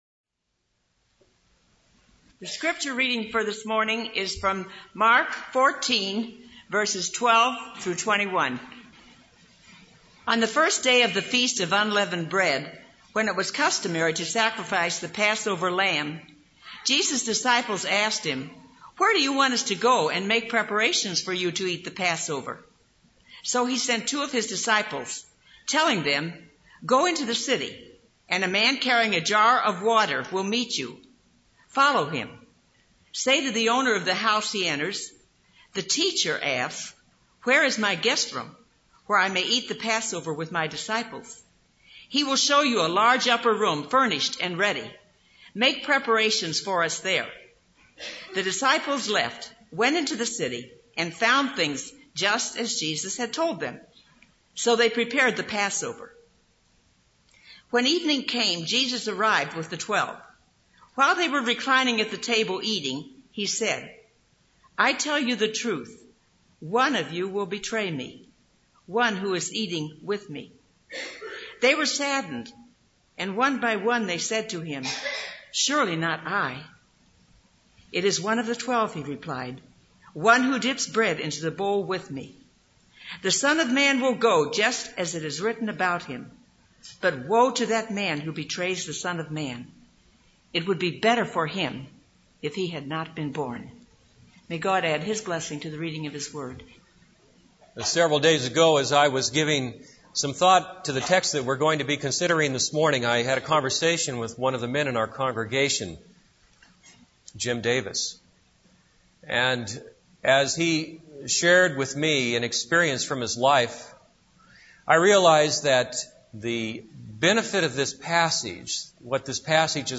This is a sermon on Mark 14:12-20.